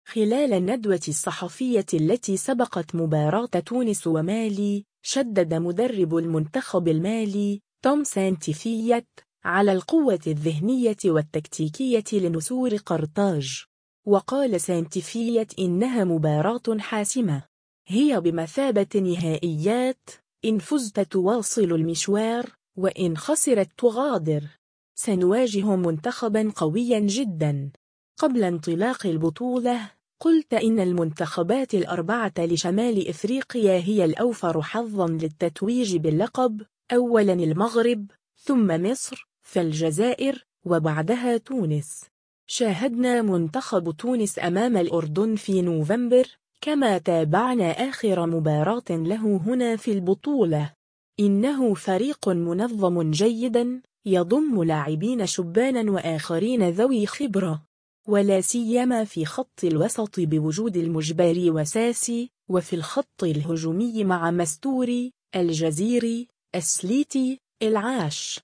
خلال الندوة الصحفية التي سبقت مباراة تونس ومالي